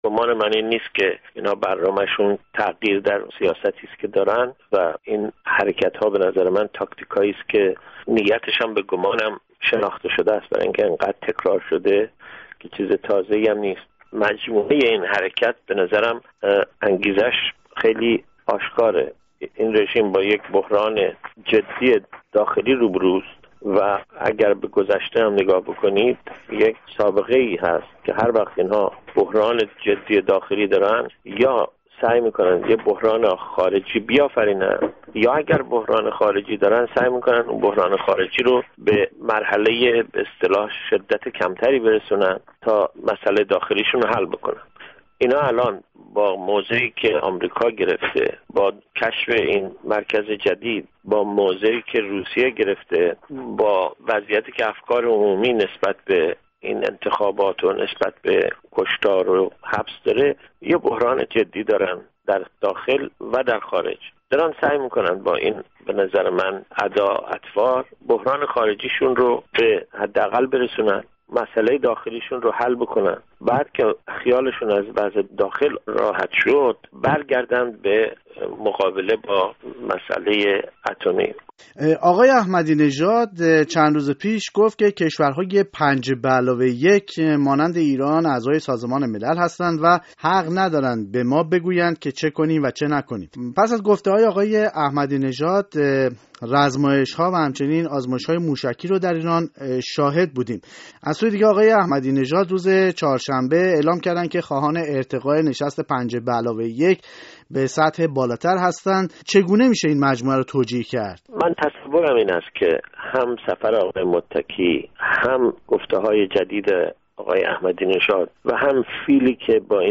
گفت‌وگو با عباس میلانی، استاد دانشگاه استنفورد